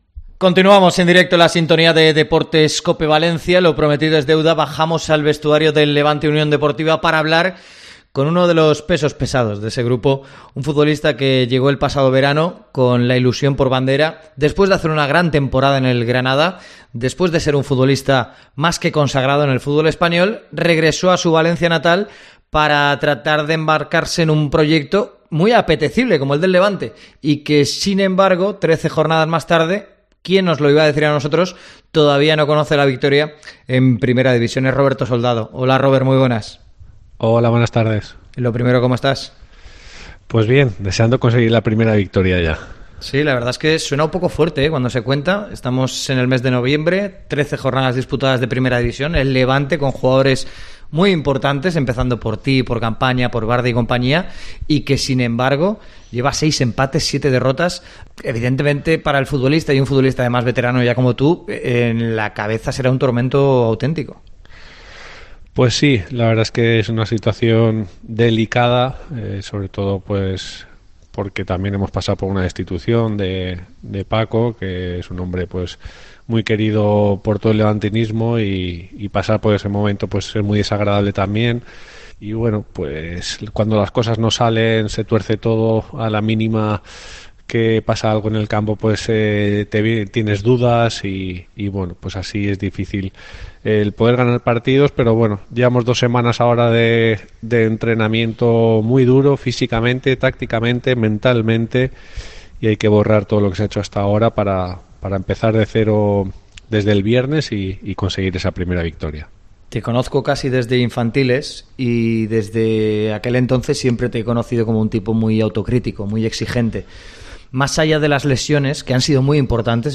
Entrevista a Roberto Soldado en COPE